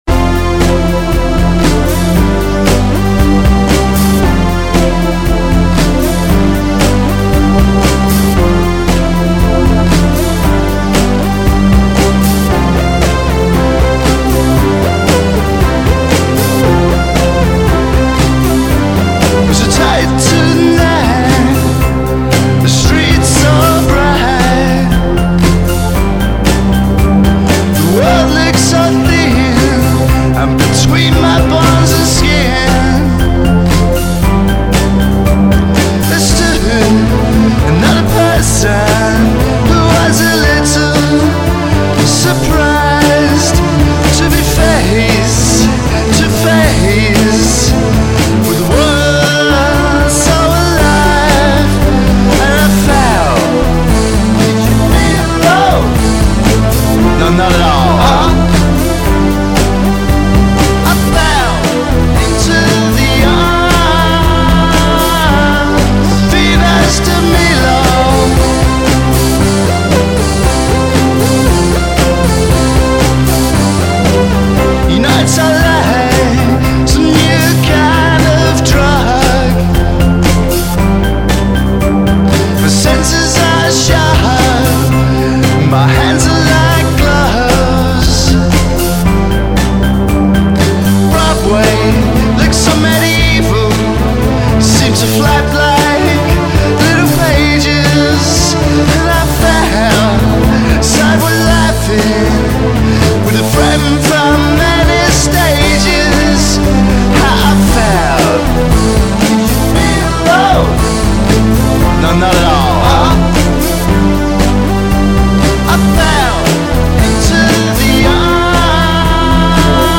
A post punk pop group from Cornwall